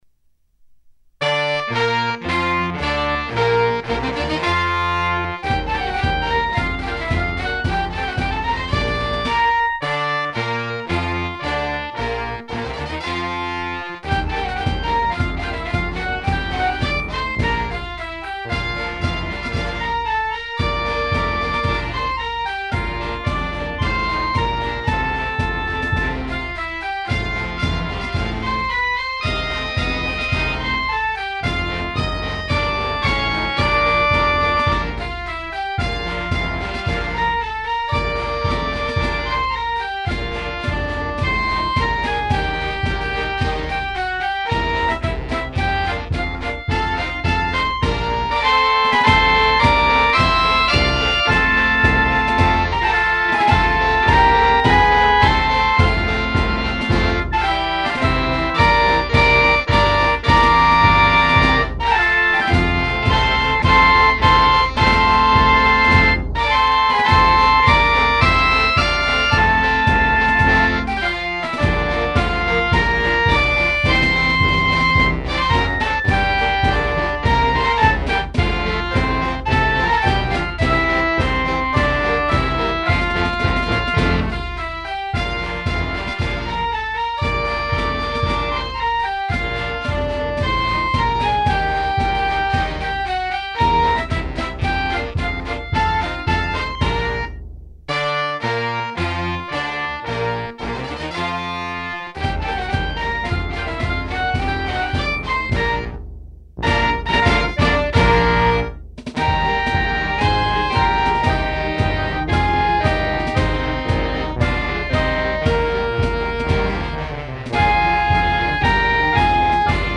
Le pépère Pas redoublé de J-B Lemire
Pour orchestre